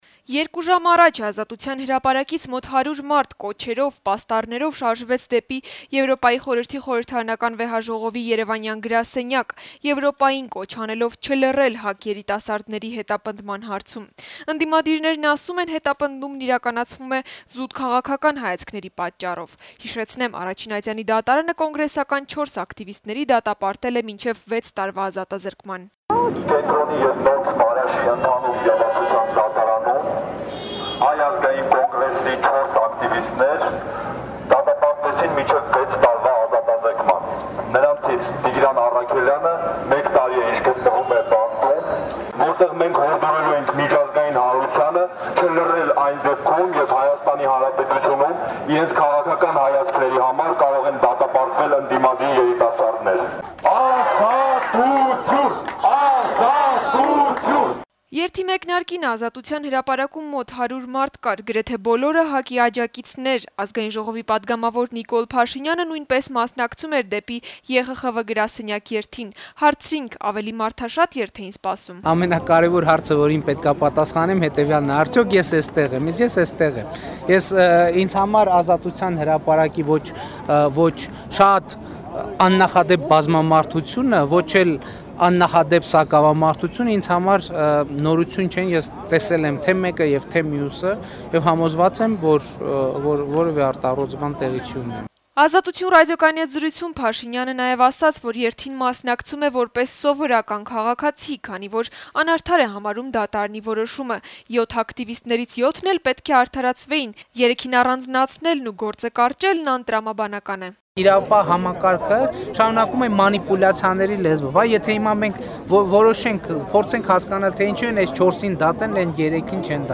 Այսօր Ազատության հրապարակից մոտ 100 մարդ վանկարկումներով, պաստառներով շարժվեցին դեպի ԵԽԽՎ երեւանյան գրասենյակ` կոչ անելով չլռել ՀԱԿ երիտասարդների հետապնդման հարցում: